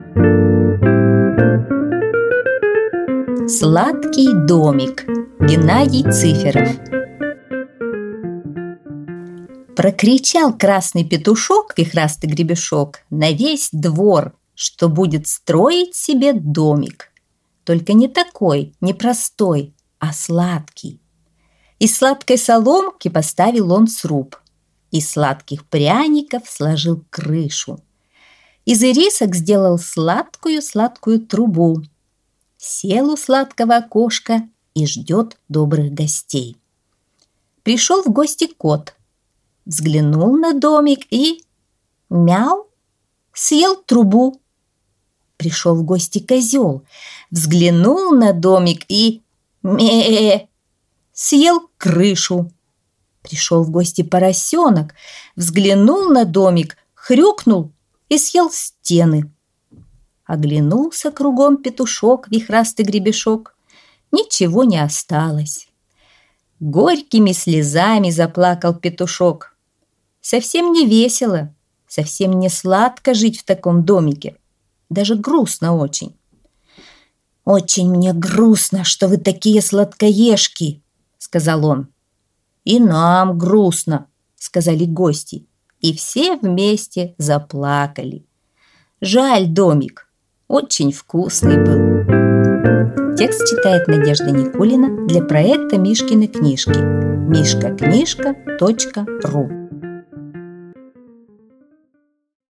Аудиосказка «Сладкий домик»